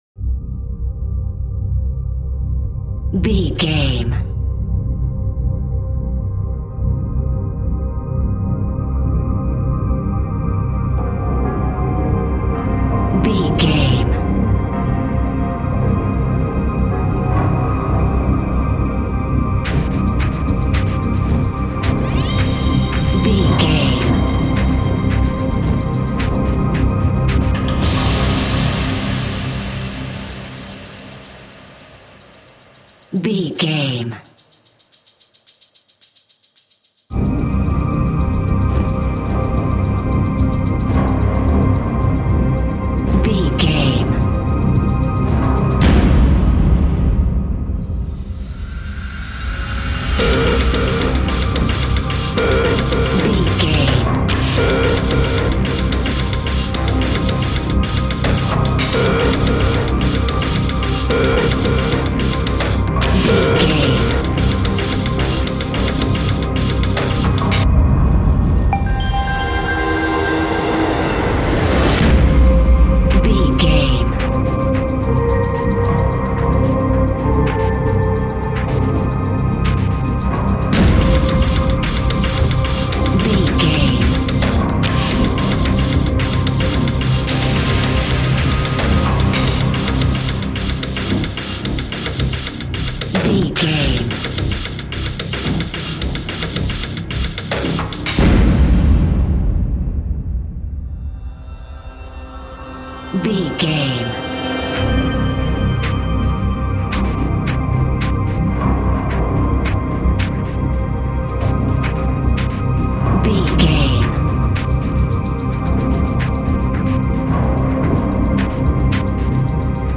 Epic / Action
Fast paced
In-crescendo
Thriller
Ionian/Major
industrial
dark ambient
EBM
drone
synths